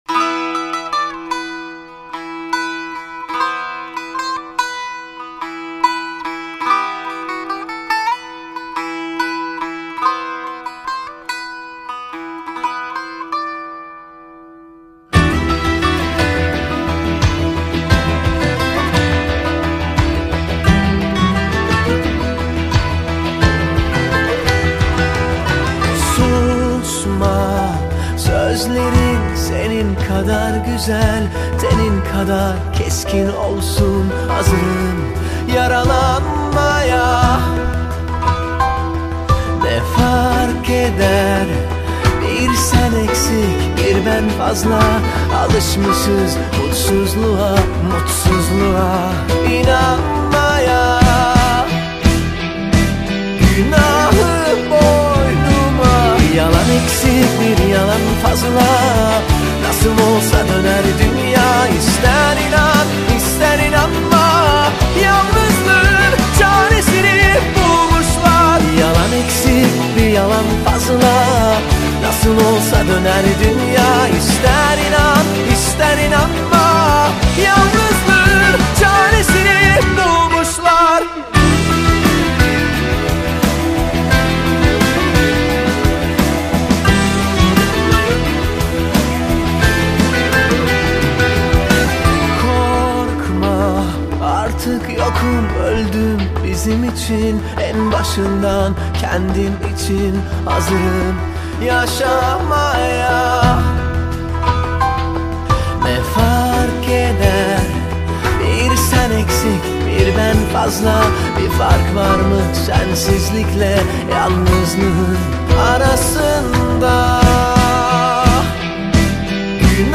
Turkish Pop, Pop, Turkish Folk Music, Arabesque